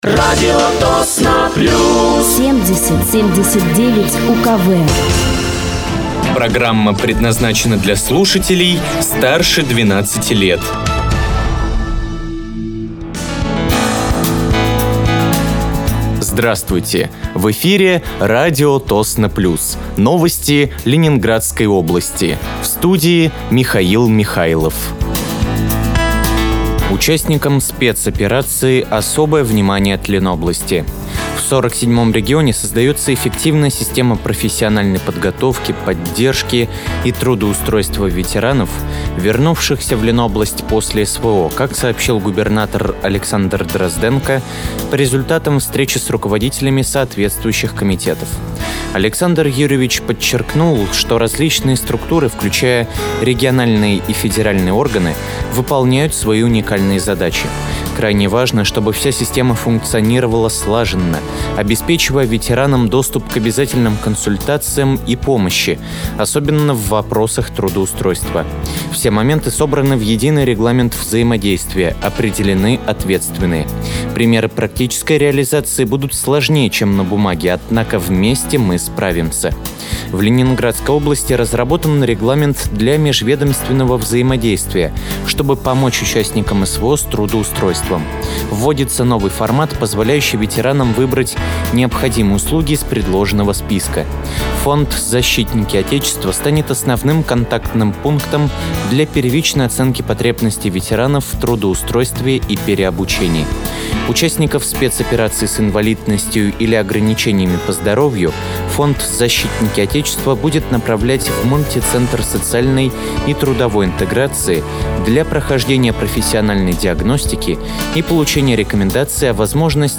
Новости Ленинградской области от 24.01.2025
Вы слушаете новости Ленинградской области от 24.01.2025 на радиоканале «Радио Тосно плюс».